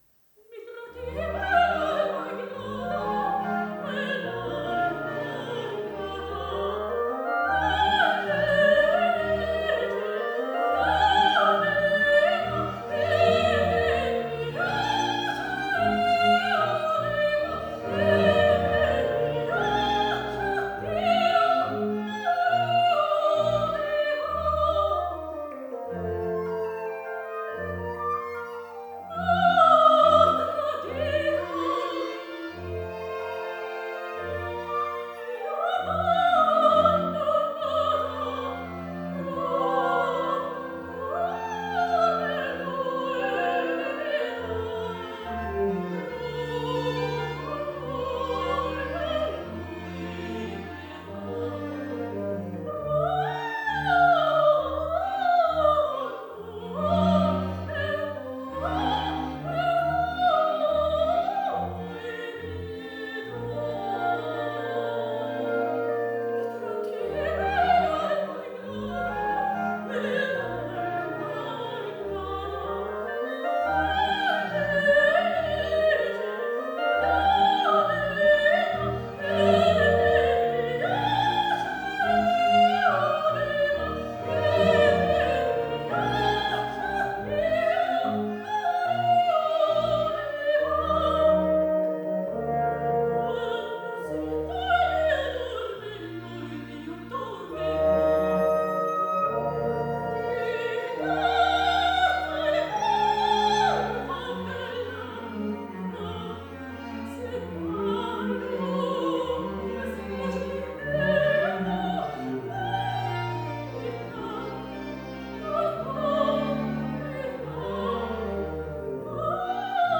MOZART_HolyTrinity_TimeDomainP1.wav